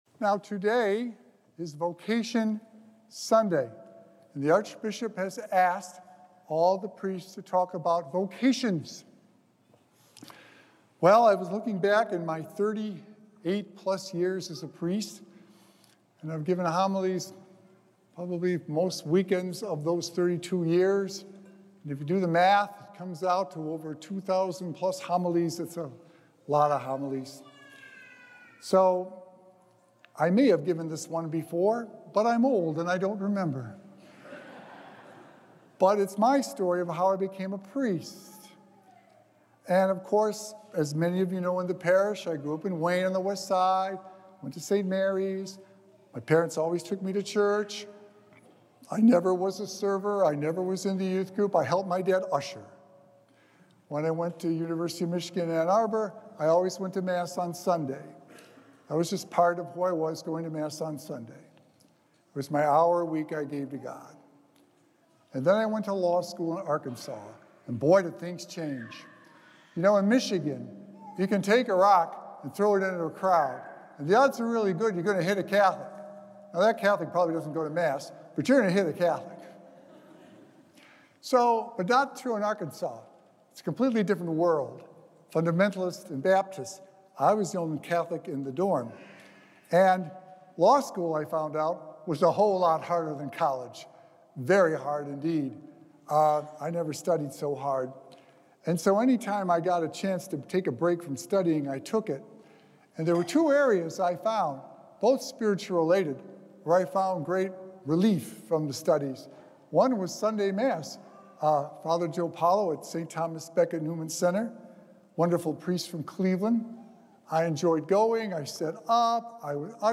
Sacred Echoes - Weekly Homilies Revealed
Recorded Live on Sunday, July 13th, 2025 at St. Malachy Catholic Church.